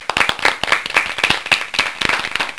snd_9000_clapping!.wav